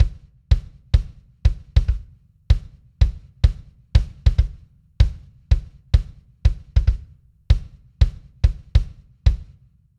キック
まず、リズムの最も低音部を支えるのがバスドラム、通称「キック」です。
この「ズン」という重たい響きは、文字通りリズムの“重み”をどこに置くのかを決定づける存在となっています。
r1-essentials-kick.mp3